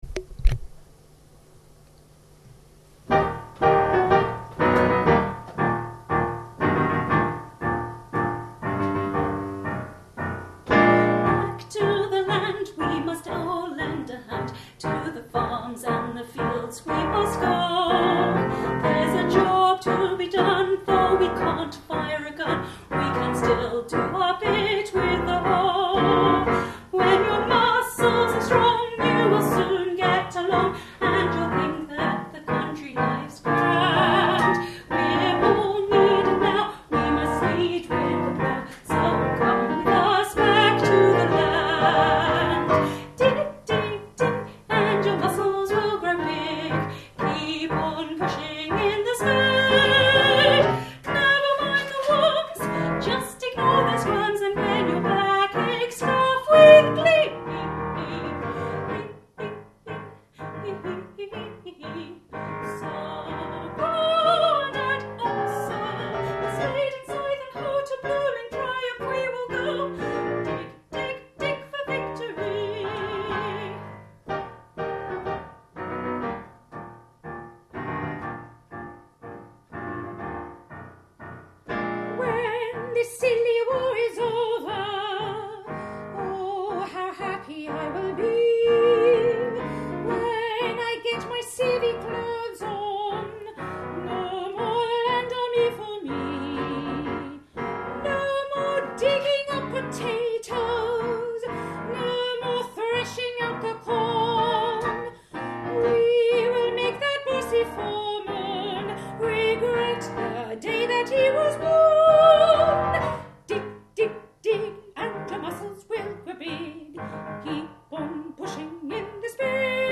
Vocal & Piano Audio of Land Army